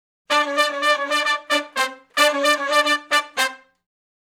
Index of /90_sSampleCDs/Sonic Foundry (Sony Creative Software) - Crimson Blue and Fabulous Horncraft 4 RnB/Horncraft for R&B/Sections/011 Funk Riff
011 Funk Riff (C) unison.wav